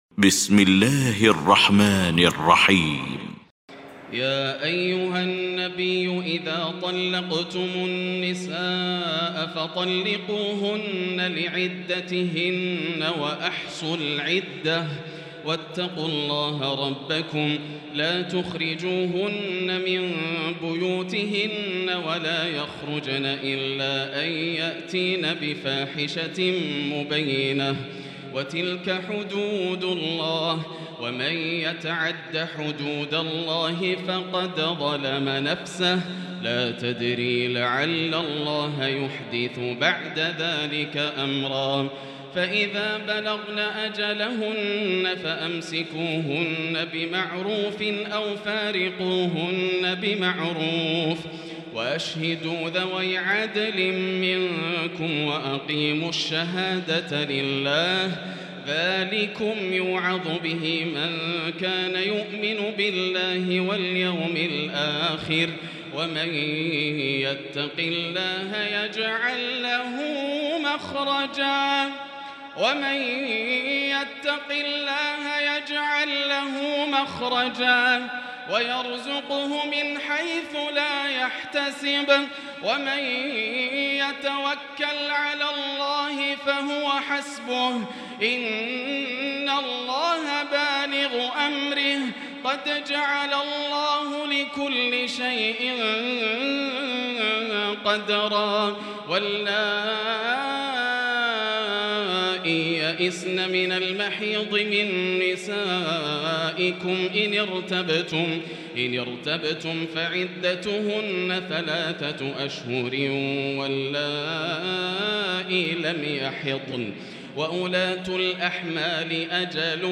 المكان: المسجد الحرام الشيخ: فضيلة الشيخ ياسر الدوسري فضيلة الشيخ ياسر الدوسري الطلاق